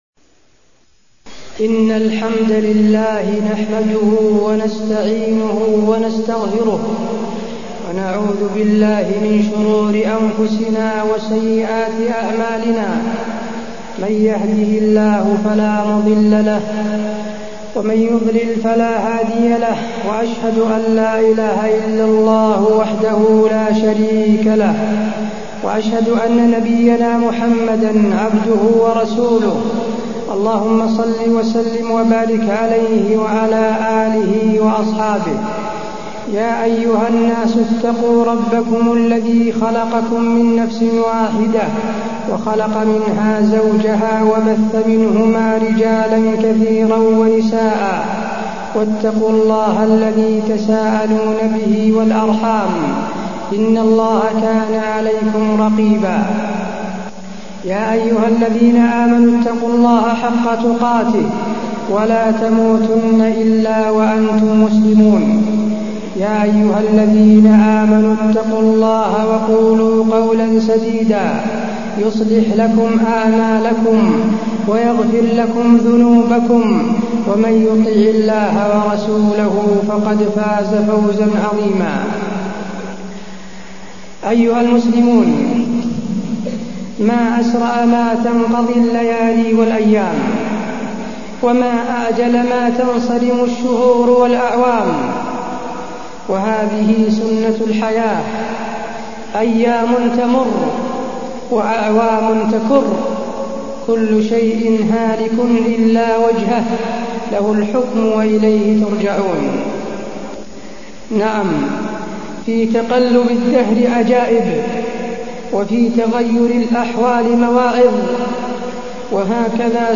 تاريخ النشر ٢٦ رمضان ١٤٢١ هـ المكان: المسجد النبوي الشيخ: فضيلة الشيخ د. حسين بن عبدالعزيز آل الشيخ فضيلة الشيخ د. حسين بن عبدالعزيز آل الشيخ العشر الأواخر